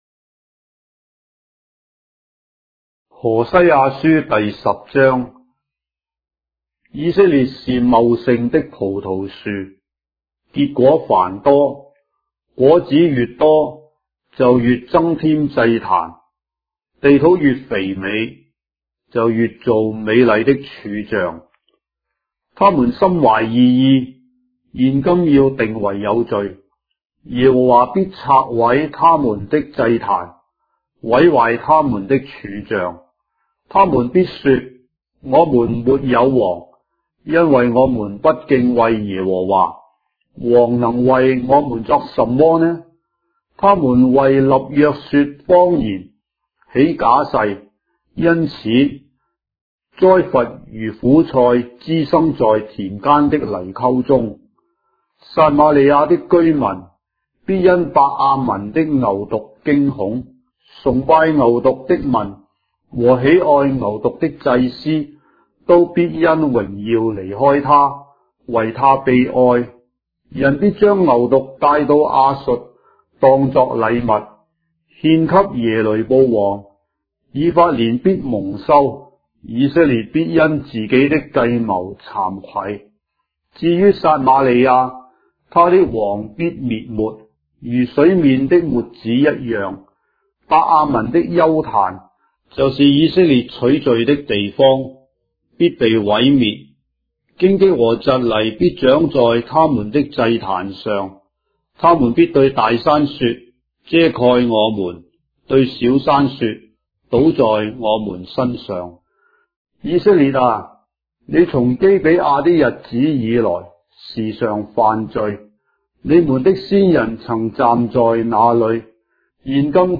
章的聖經在中國的語言，音頻旁白- Hosea, chapter 10 of the Holy Bible in Traditional Chinese